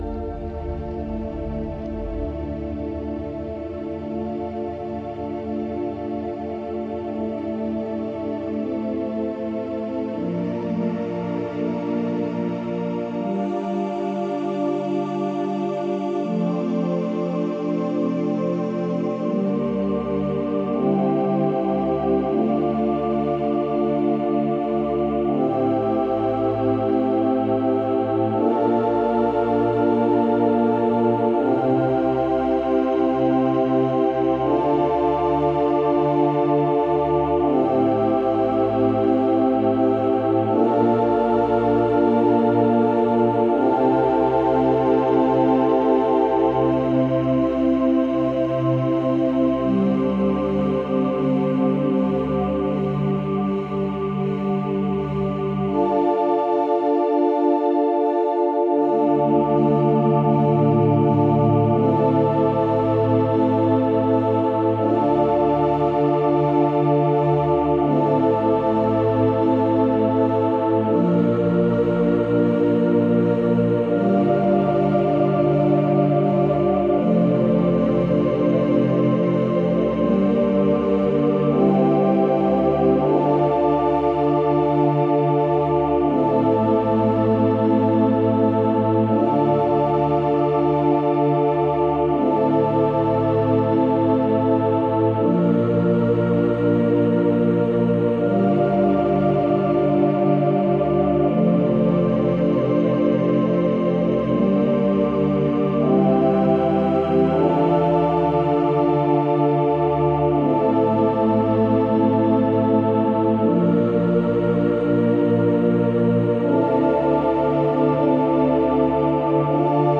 Healing, meditative ambient new age.
Tagged as: Ambient, New Age, Space Music